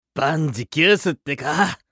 熱血系ボイス～戦闘ボイス～